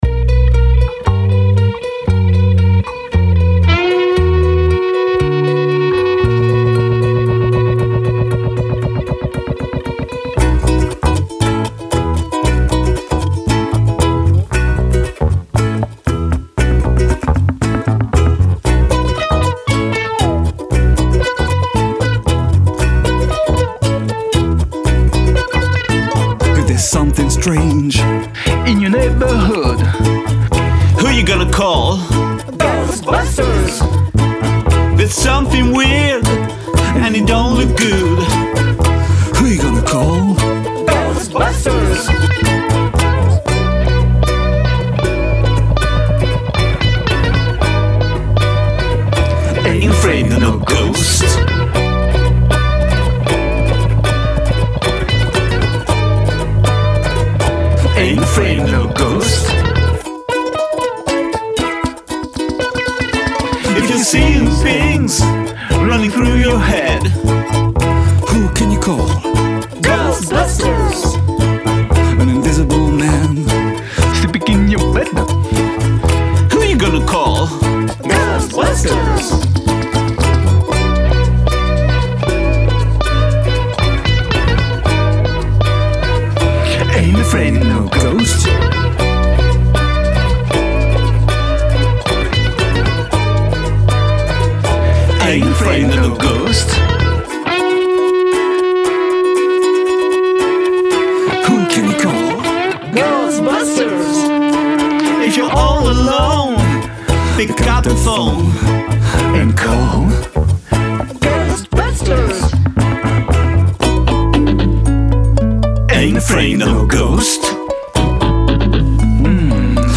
Un petit lipdub pour une reprise d'un tube des années 80.
Voix - Guitare
Ukulele - Voix - Percussions - Sampler